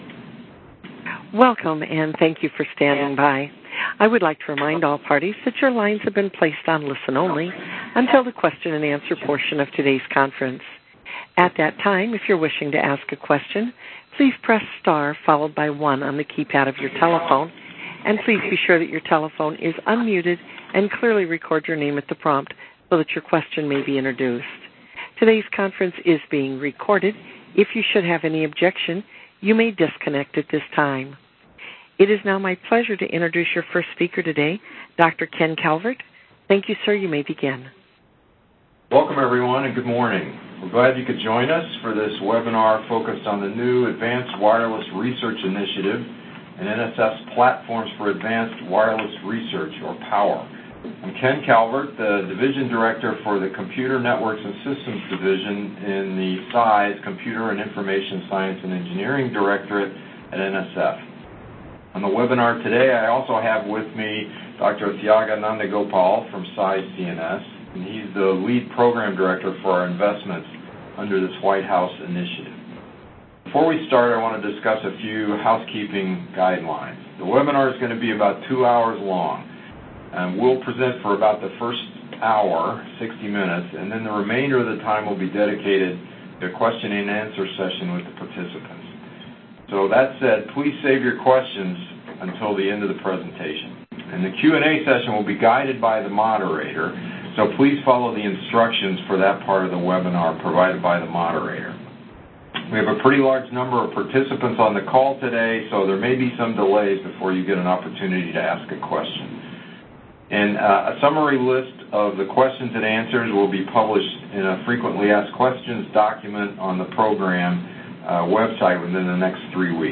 PAWR Webinar